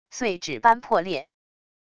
碎纸般破裂wav音频